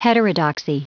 Prononciation du mot heterodoxy en anglais (fichier audio)